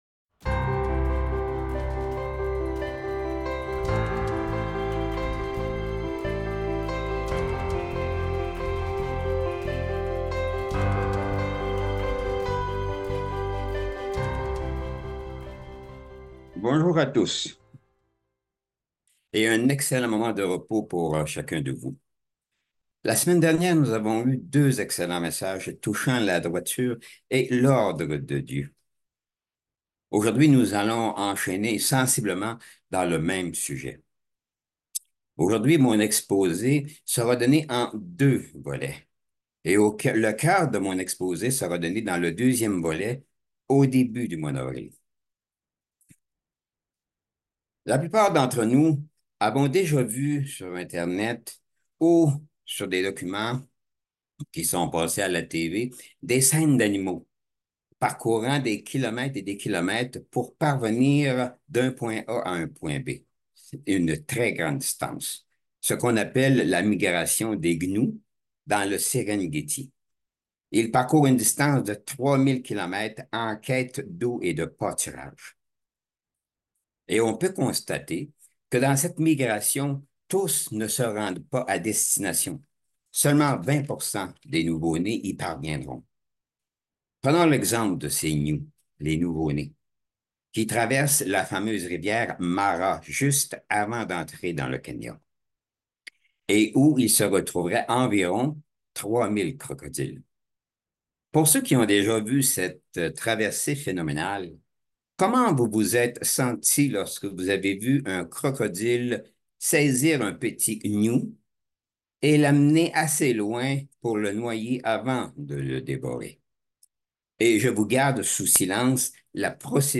Given in Bordeaux